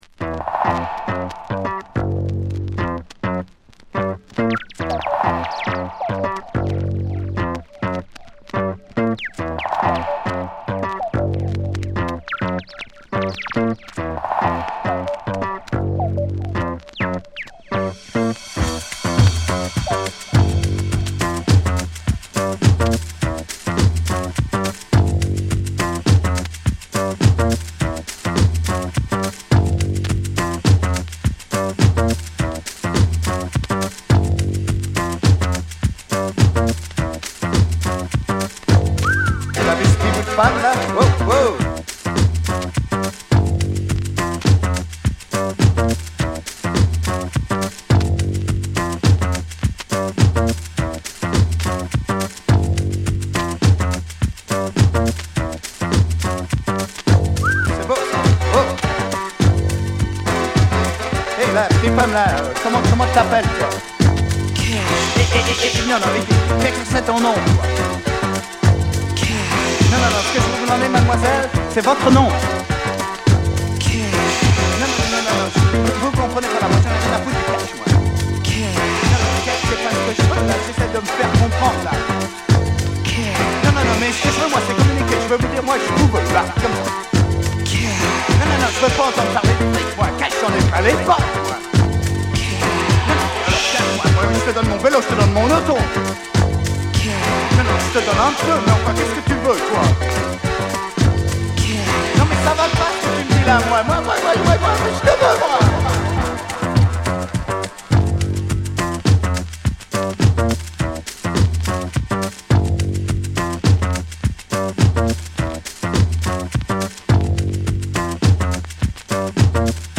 スペイン？地中海の香りの言語が飛び交う